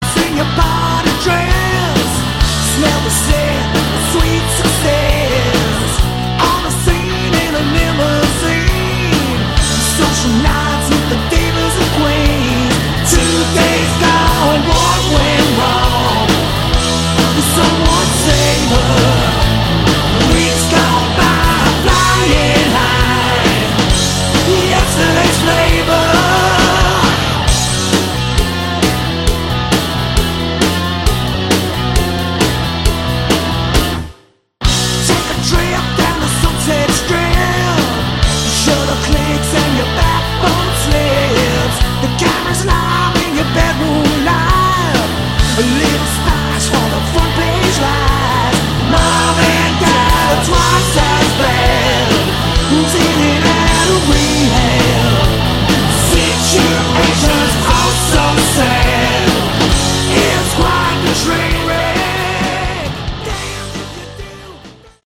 Category: Hard Rock
lead vocals, harp
guitars, vocals
guitar, mandolin
bass, vocals
drums, percussion